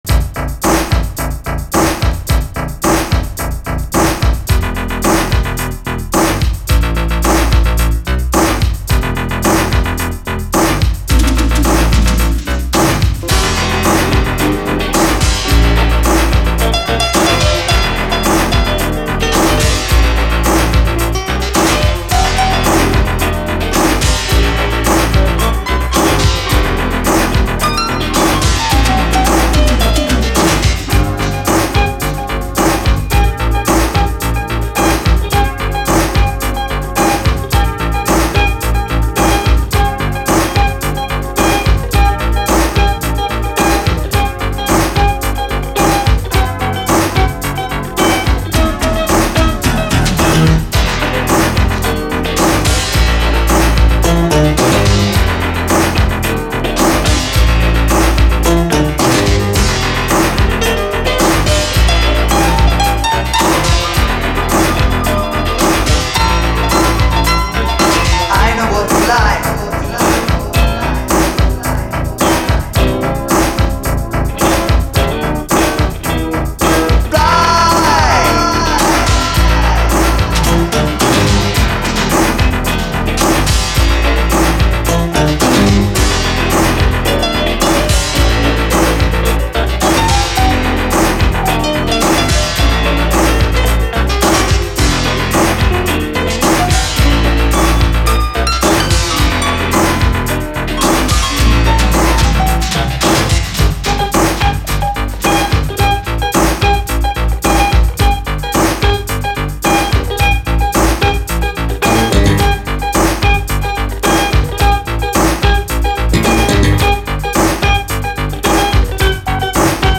DISCO
毒気たっぷり、甘く妖しいダーク・イタロ・ディスコ！ピアノ入りのインストがさらにカッコいい。
ビキビキの攻撃的なシンセとメロウネスが同居する、ダーク・イタロ・ディスコ！